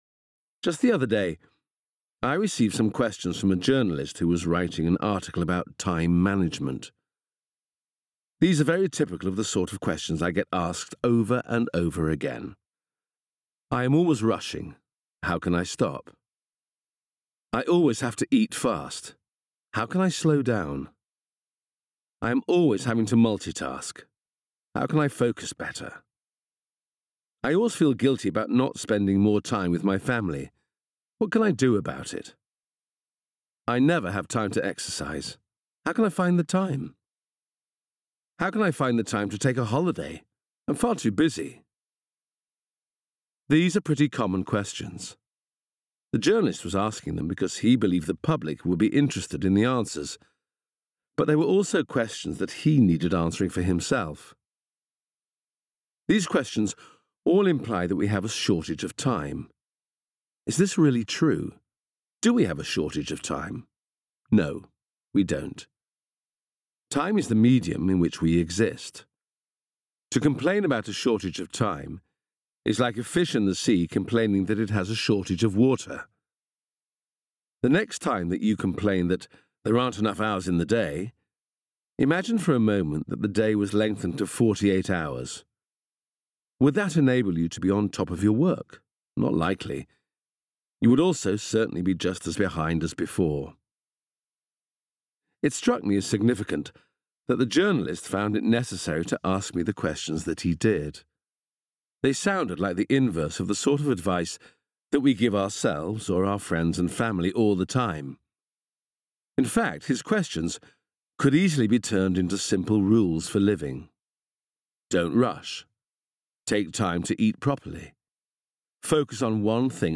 Do It Tomorrow and Other Secrets of Time Management (By Mark Foster) Audiobook
Narration - Do It Tomorrow